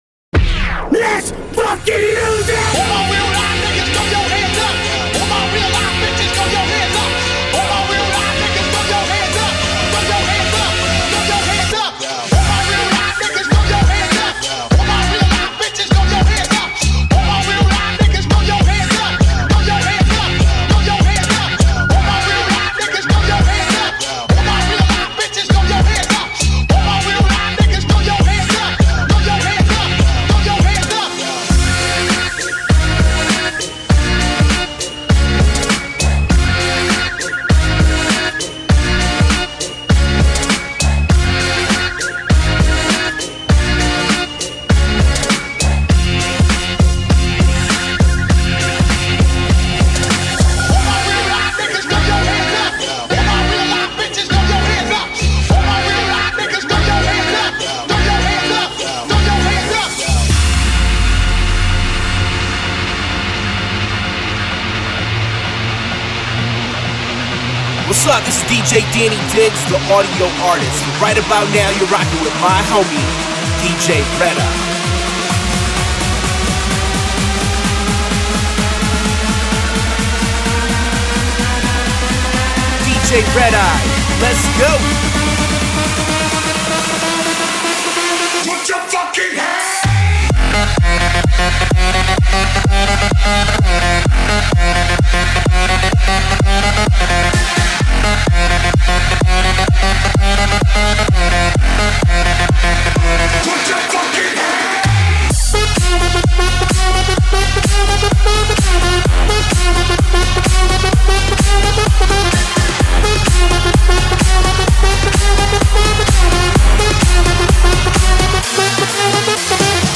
Open Format DJ
high energy sets